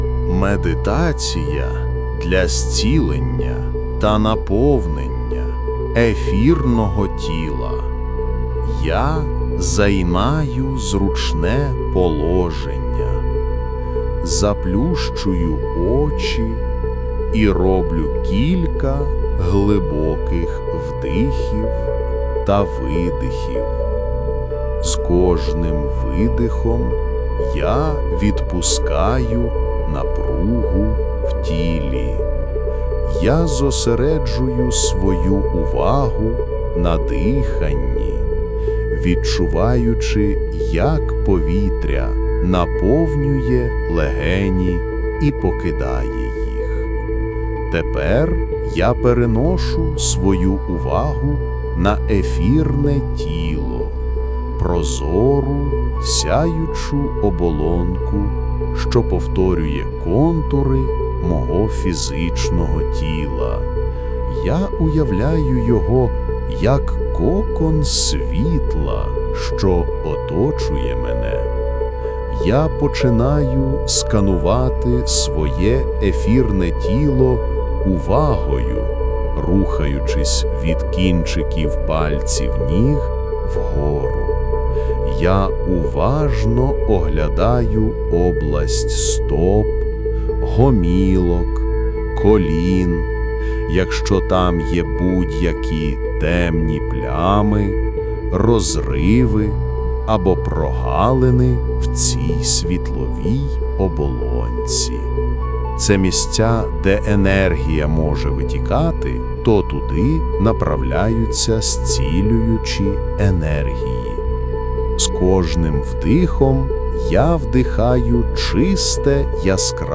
Медіаресурси згенеровані в Google AI Studio (включно із зображенням), з мого боку зліпелно це в однин файл із мінімальними правками.
Музичний фон теж трішки кращий за попередню медитацію для створення енергетичного фільту.